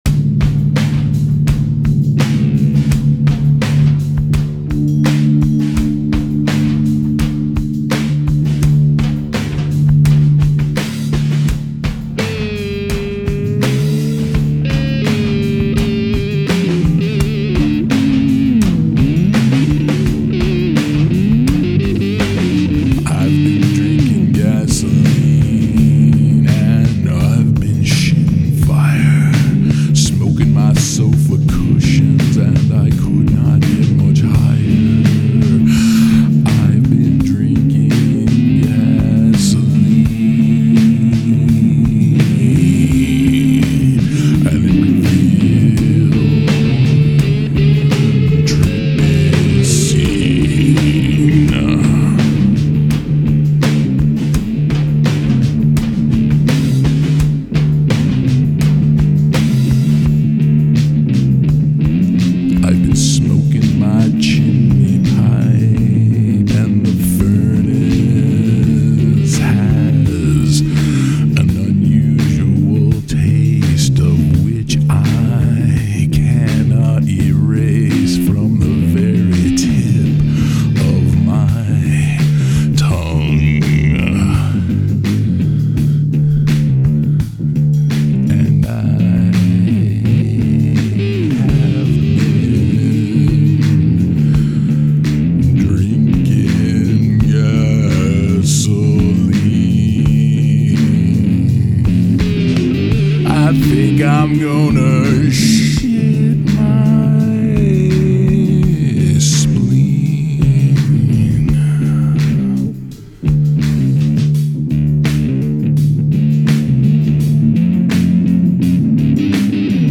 rockin’ heavy groove kind-o track! Then I sang this over it.